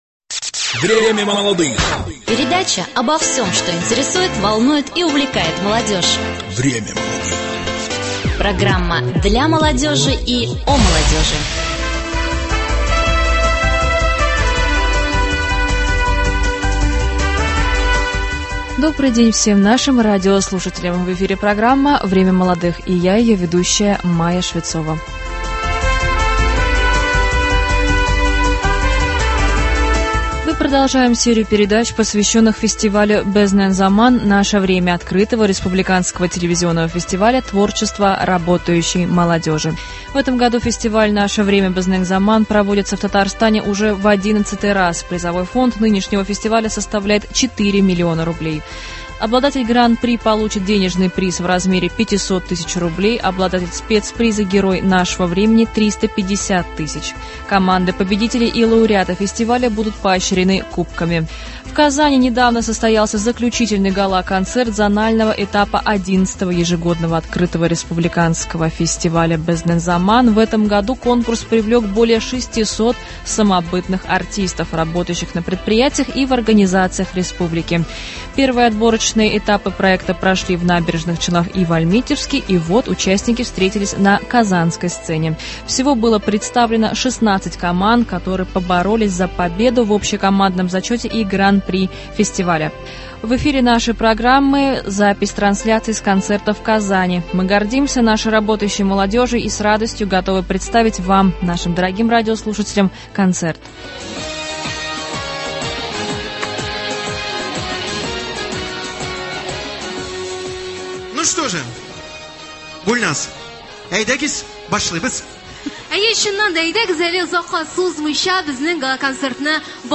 Интервью с участниками фестиваля рабочей молодежи «Безнен заман».